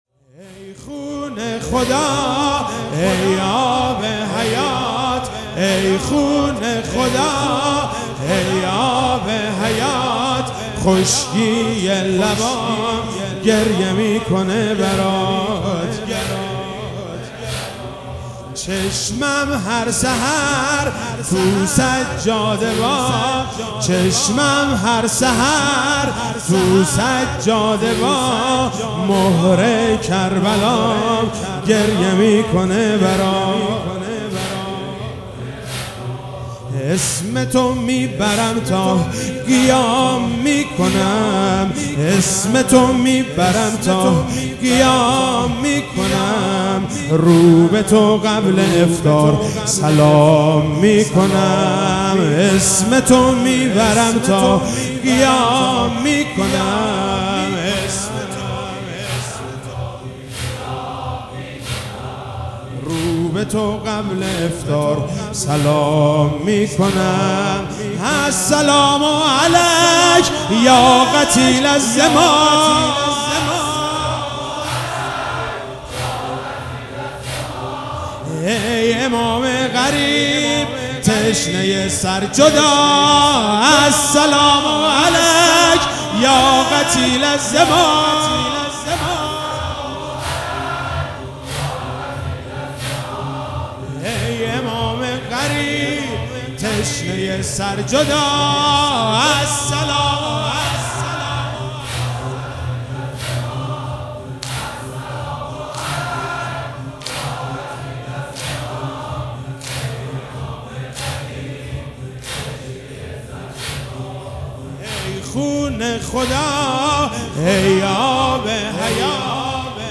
شب 23 رمضان 97 - زمینه - ای خون خدا ای آب حیات